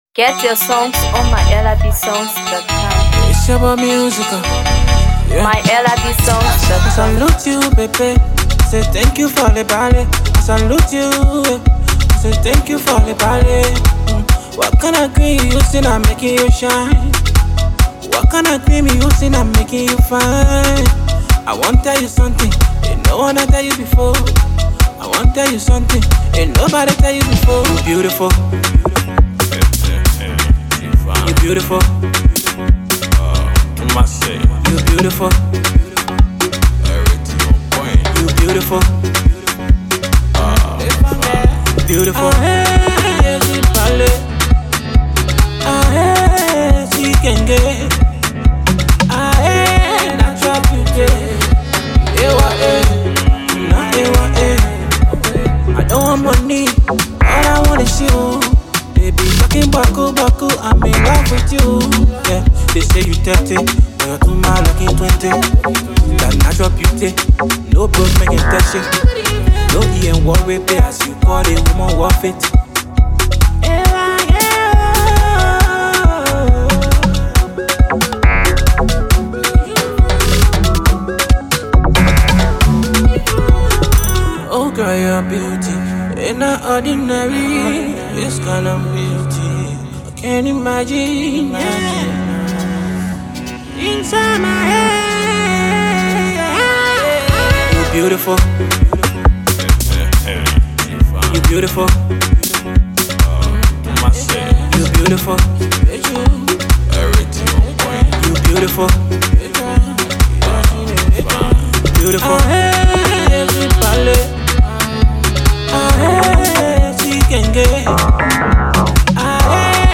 Afro Pop
Liberian Afrobeat
With his smooth vocals, addictive rhythm